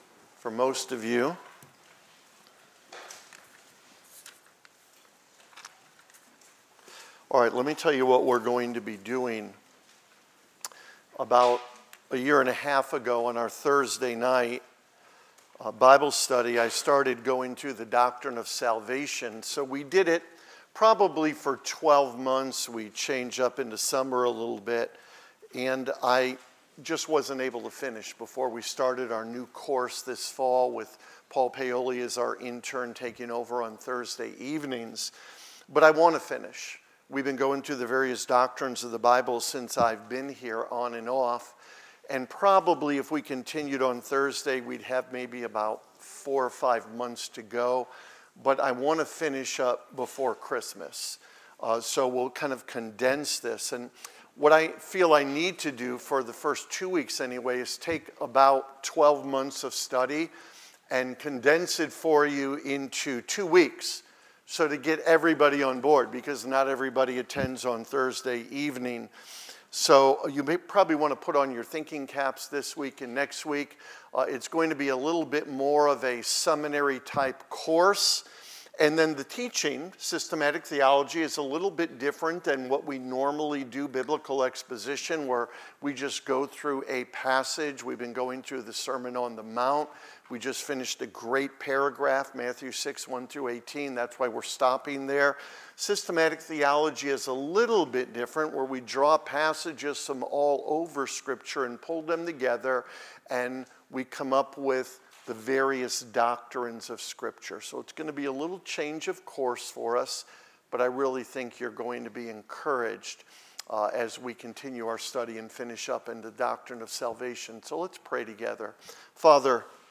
11.3.24-Sermon.mp3